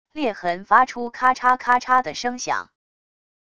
裂痕发出咔嚓咔嚓的声响wav音频